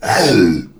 spawners_mobs_uruk_hai_hit.2.ogg